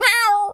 pgs/Assets/Audio/Animal_Impersonations/cat_scream_01.wav at master
cat_scream_01.wav